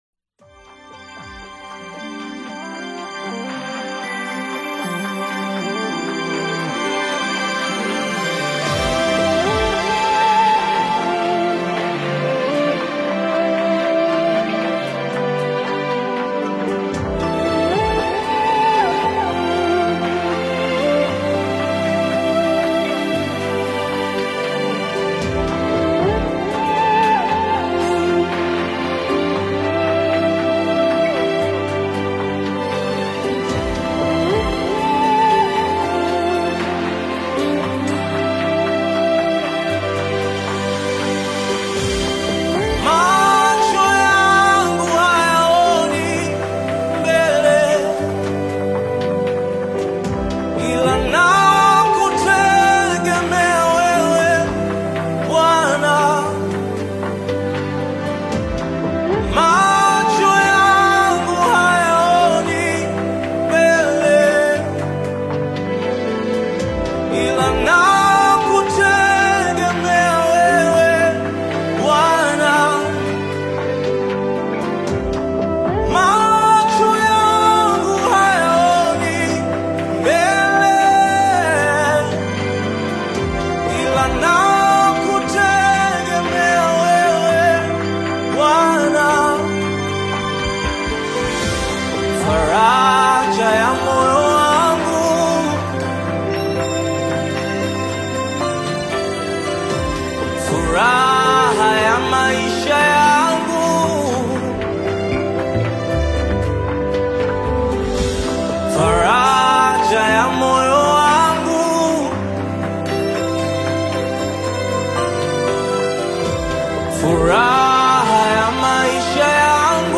rich and emotive vocals
enchanting harmonies, crafting a harmonious soundscape